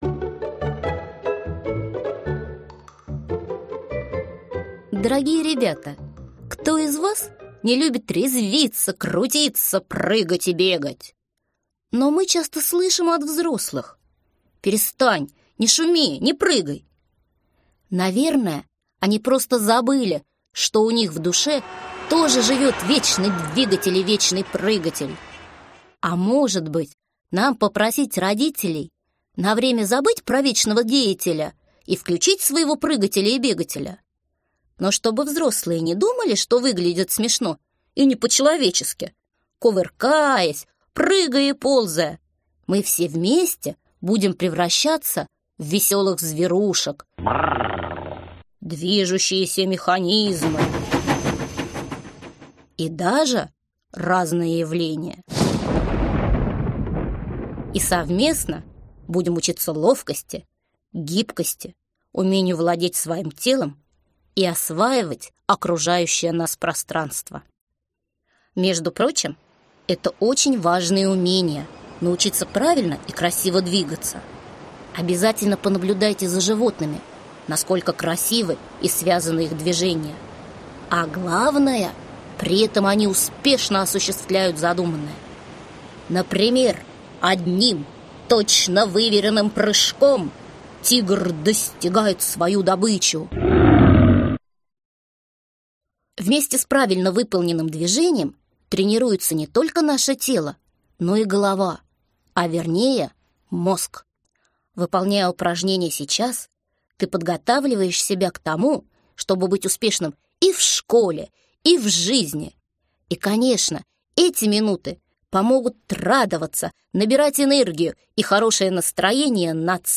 Аудиокнига Аудиокурс «Гимнастика для детей» | Библиотека аудиокниг